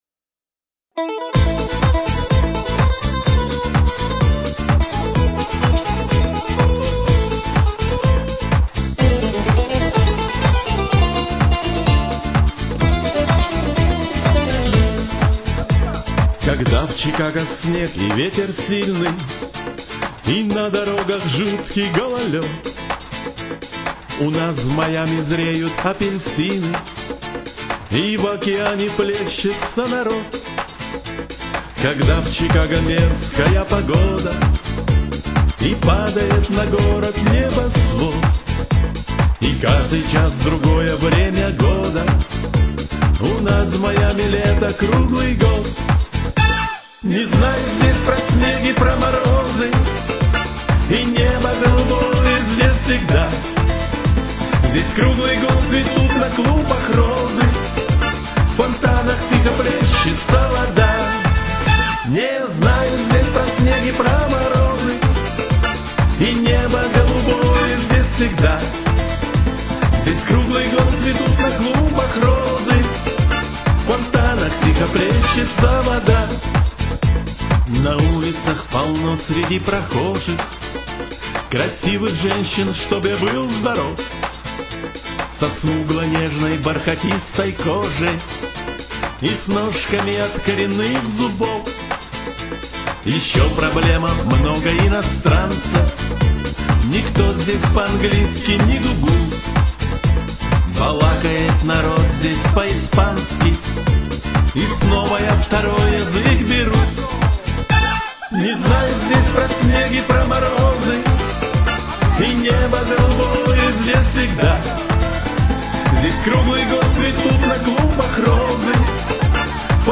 У нас в гостях  автор и исполнитель